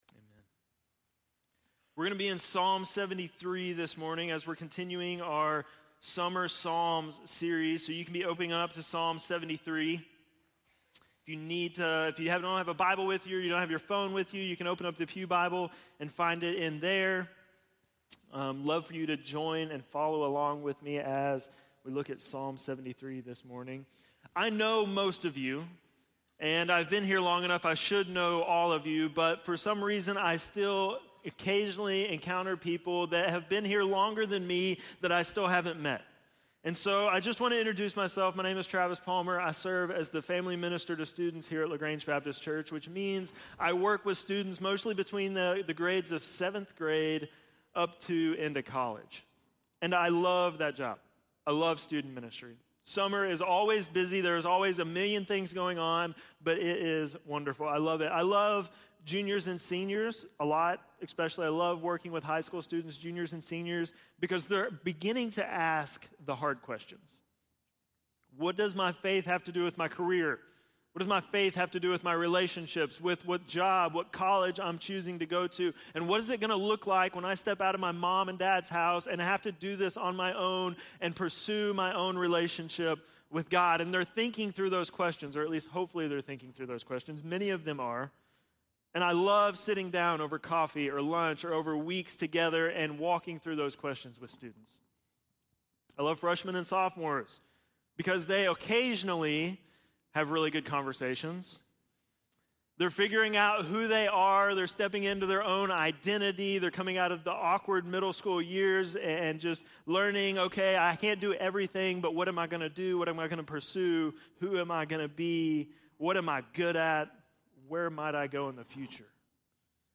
7.14-sermon.mp3